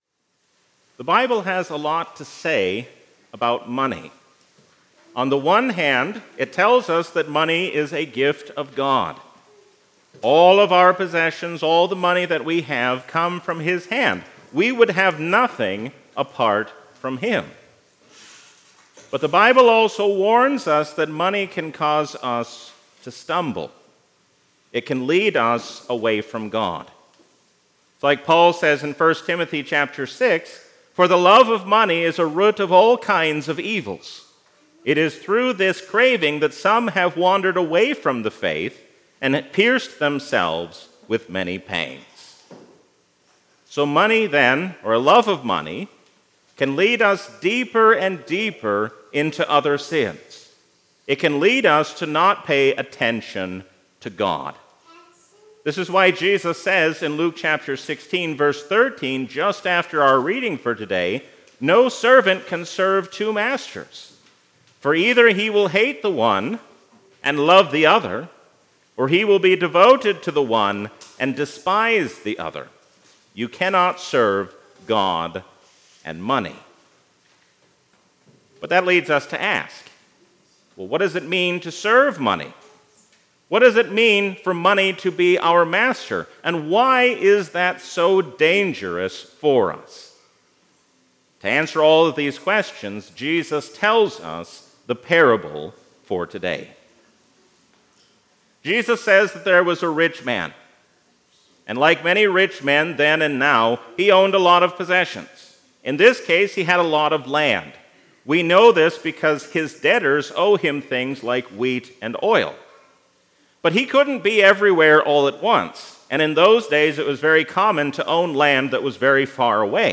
A sermon from the season "Trinity 2023."